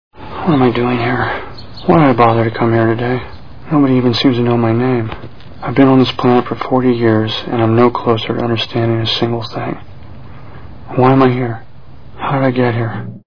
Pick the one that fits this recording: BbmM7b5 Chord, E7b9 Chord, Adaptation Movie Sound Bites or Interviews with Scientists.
Adaptation Movie Sound Bites